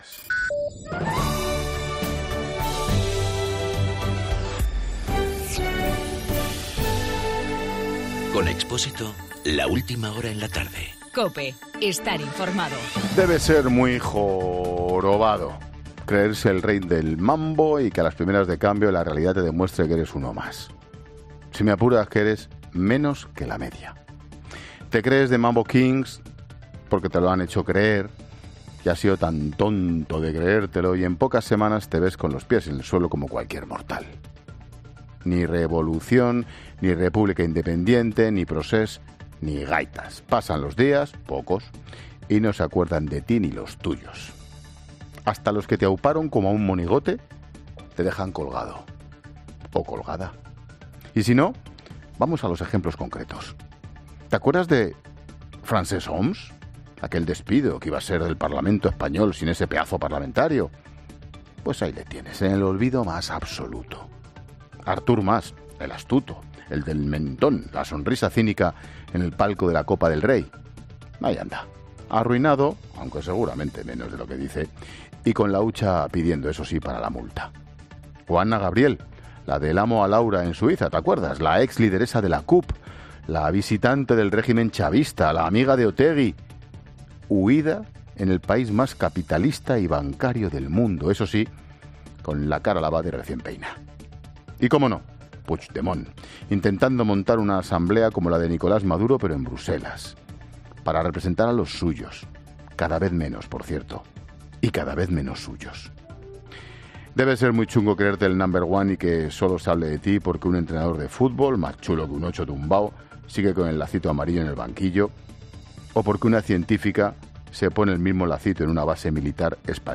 Monólogo de Expósito
Escucha ahora el monólogo de Ángel Expósito a las 18 horas en 'La Tarde'.